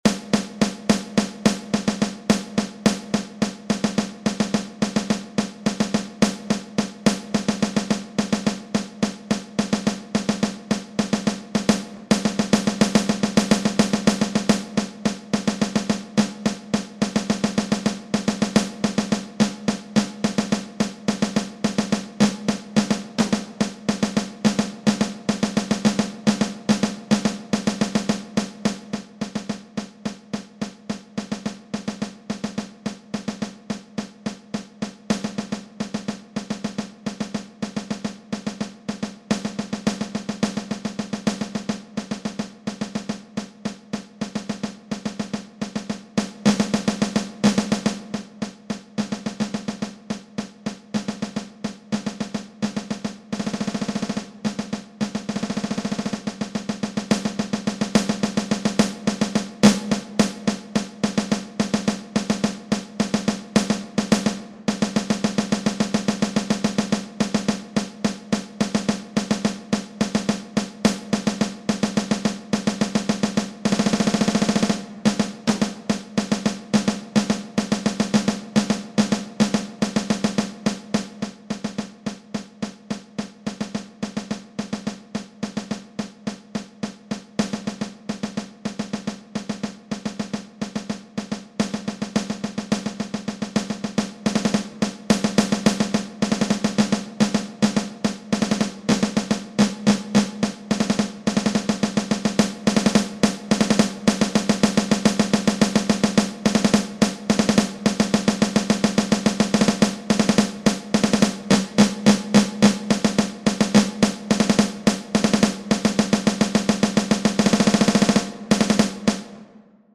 This is a snare drum solo